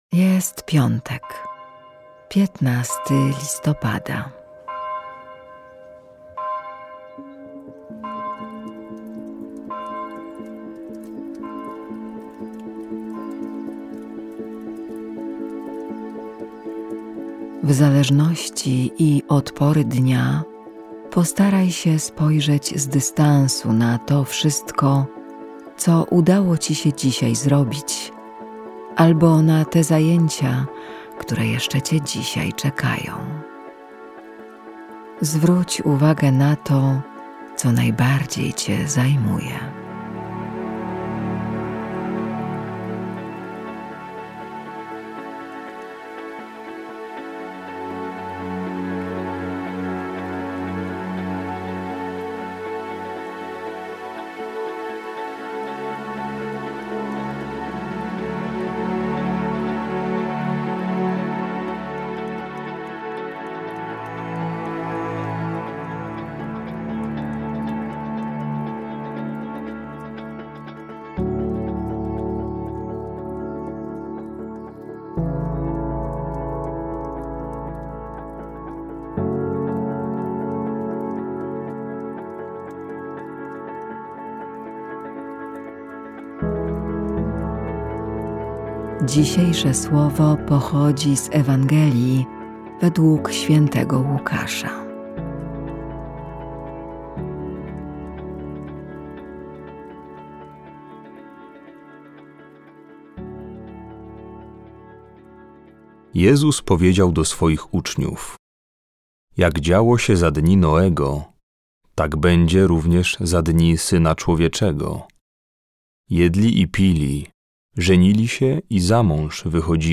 Polscy Jezuici, idąc za przykładem swoich braci z Wielkiej Brytanii, zaproponowali serie około dziesięciominutowych rozważań łączących muzykę i wersety z Biblii, pomagające odkrywać w życiu Bożą obecność i pogłębić relacje z Bogiem.